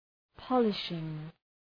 Προφορά
{‘pɒlıʃıŋ}